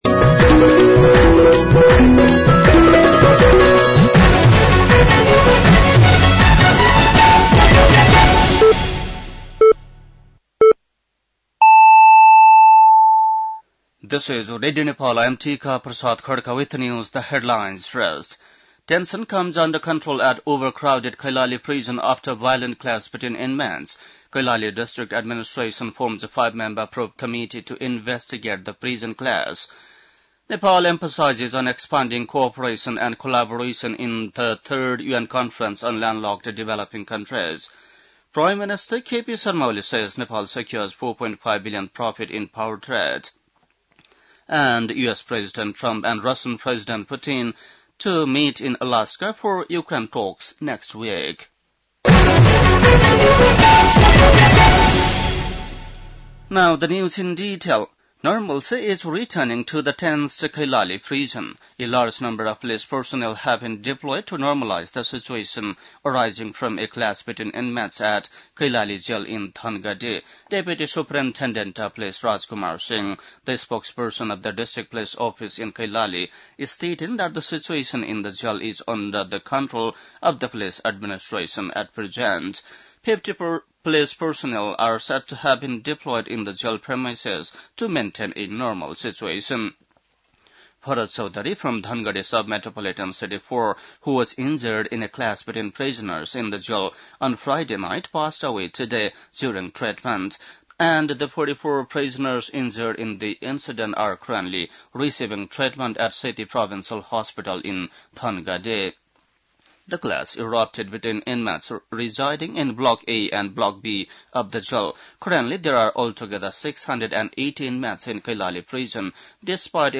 बेलुकी ८ बजेको अङ्ग्रेजी समाचार : २४ साउन , २०८२